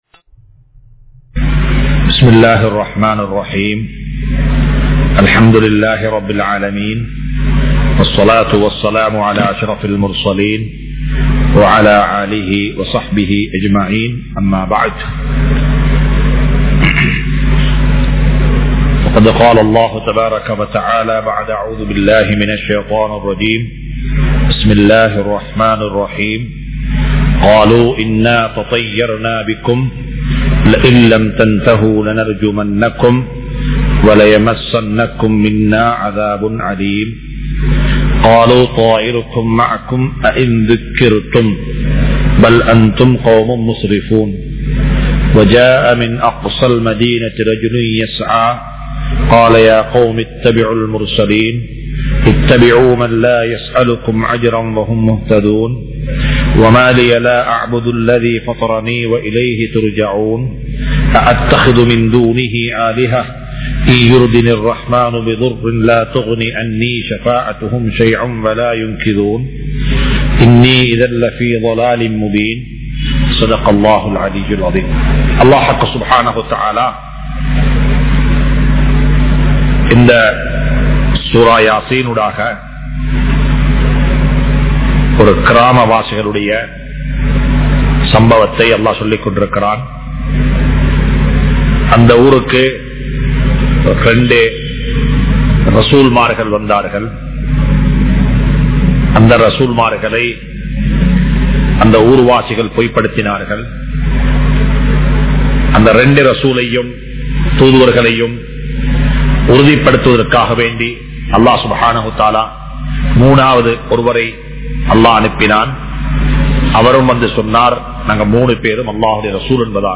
Surah Yaseen 33(Thafseer Lesson 183) | Audio Bayans | All Ceylon Muslim Youth Community | Addalaichenai